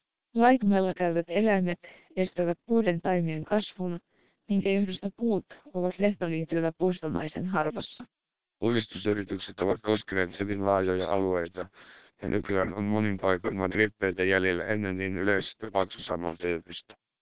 Also, all independent listeners preferred SPR, noting its significantly higher speech quality and intelligibility.